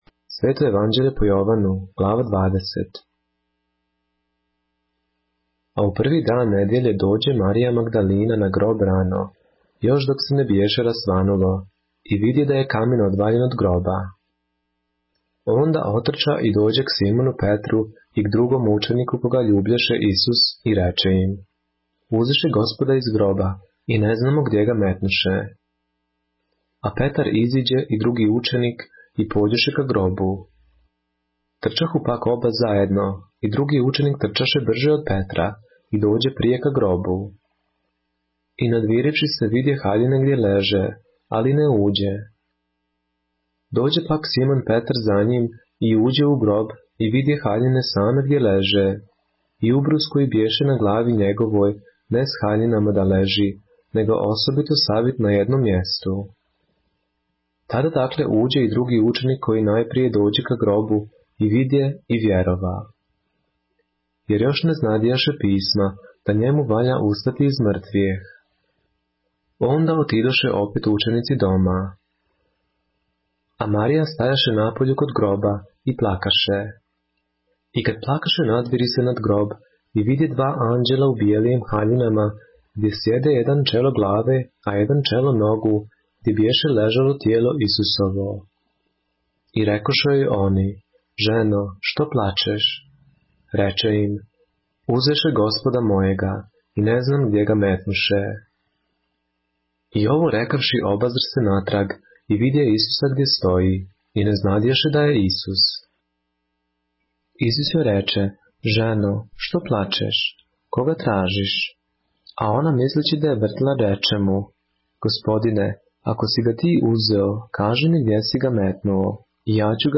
поглавље српске Библије - са аудио нарације - John, chapter 20 of the Holy Bible in the Serbian language